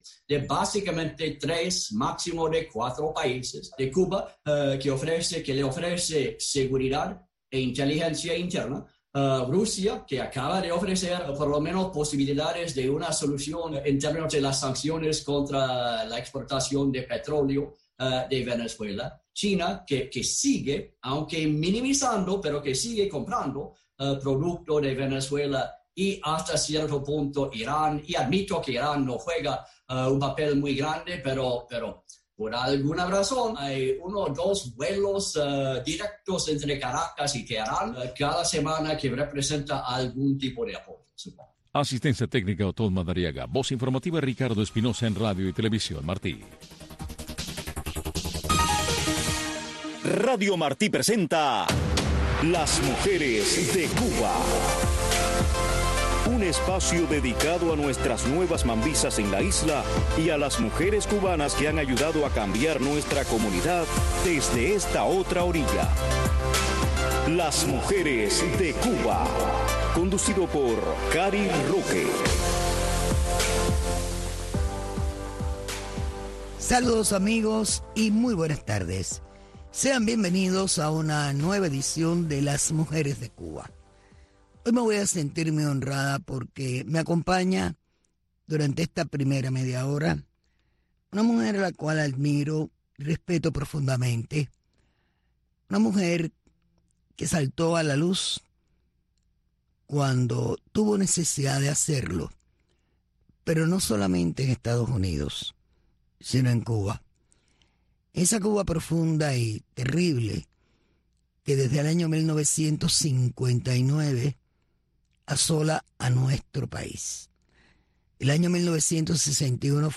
Un programa que busca resaltar a las mujeres cubanas que marcan pauta en nuestra comunidad y en la isla. Y es un acercamiento a sus raíces, sus historias de éxitos y sus comienzos en la lucha contra la dictadura. Un programa narrado en primera persona por las protagonistas de nuestra historia.